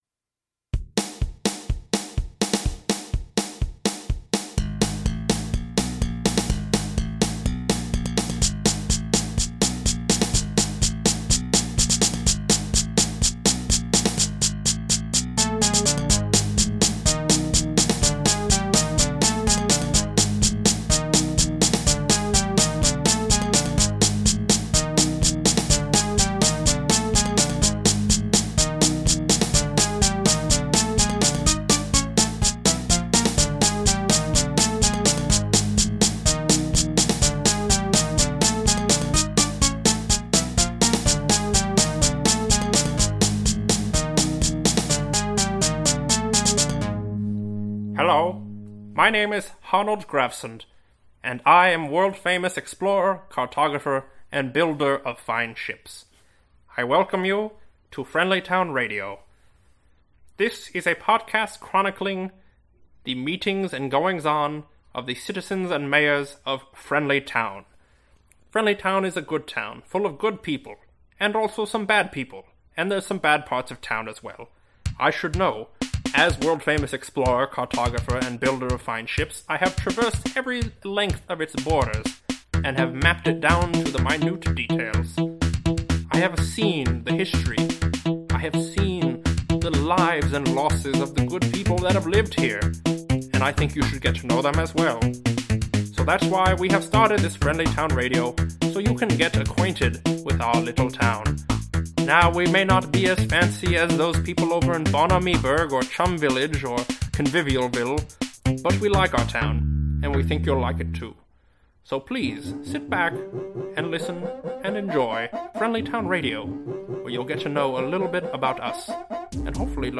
Friendlytown Radio 001: Welcome to Friendlytown | sonic comedy